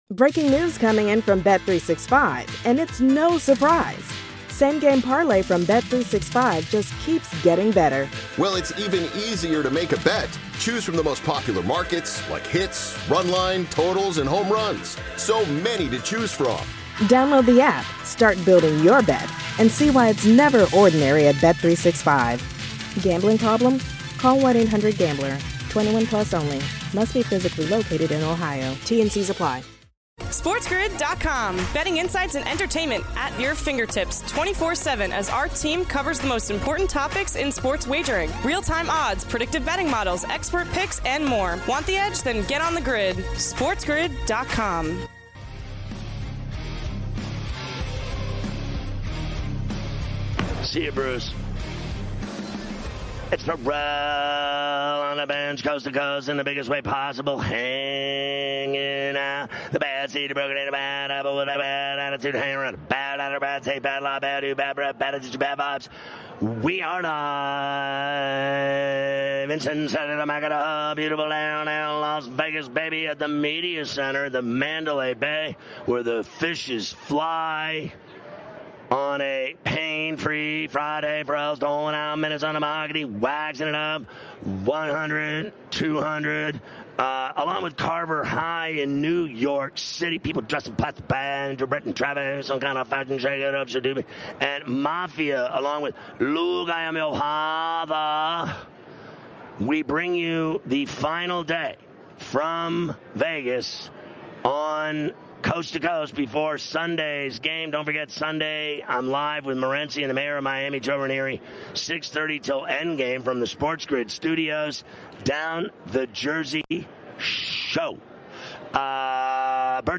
2/9 Hour 1: Ferrall Live From Radio Row in Las Vegas!
On this episode, Ferrall welcomes the biggest guests including D.J. Moore, Flavor Flav, Chris Johnson, and Donovan McNabb live from Radio Row in Las Vegas as he prepares for Super Bowl LVIII!
Legendary sports shock jock Scott Ferrall takes the gaming world by storm with his “in your face” style, previewing the evening slate of games going over lines, totals and props, keeping you out of harms way and on the right side of the line.